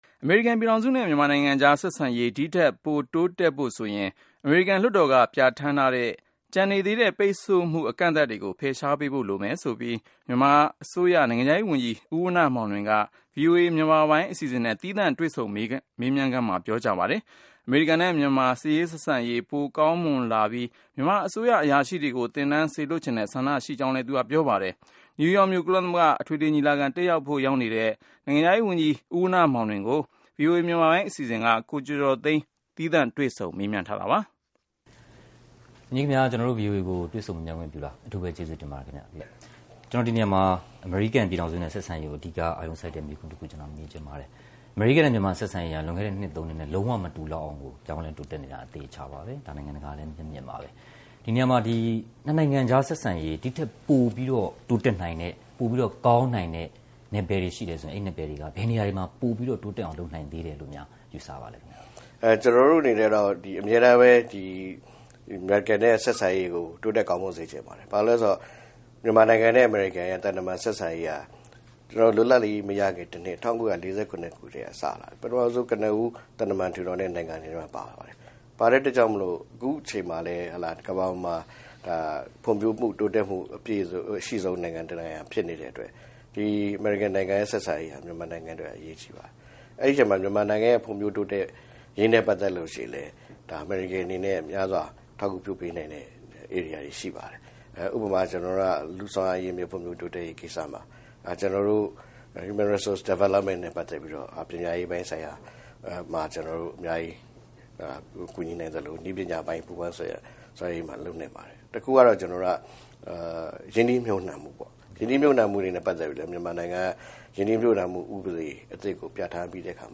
မြန်မာနိုင်ငံခြားရေးဝန်ကြီးနဲ့ ဗွီအိုအေသီးသန့်မေးမြန်းခြင်း